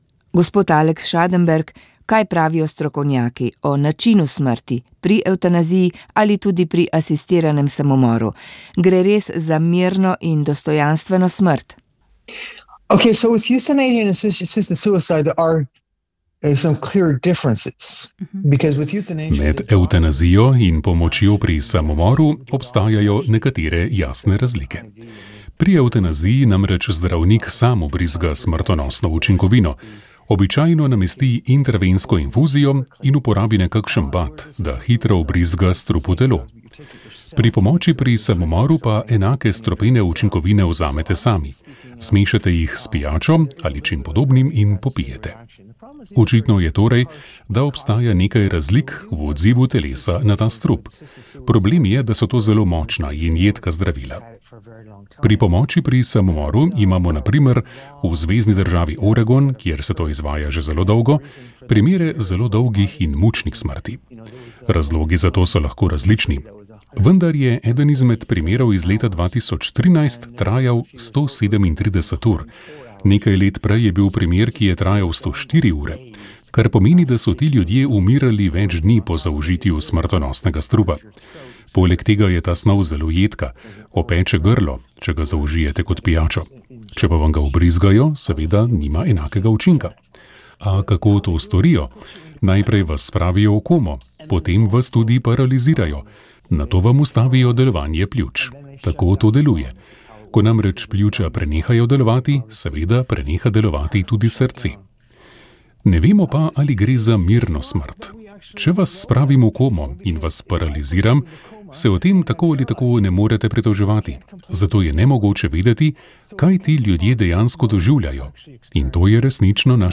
Na to je v pogovoru za Radio Ognjišče opozorila tudi evropska poslanka iz vrst SDS Romana Tomc.